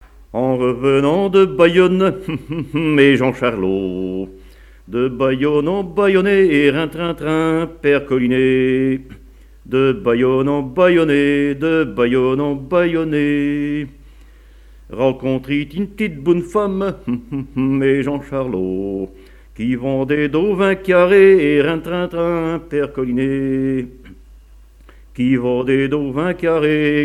Genre laisse
Pièce musicale inédite
Catégorie Pièce musicale inédite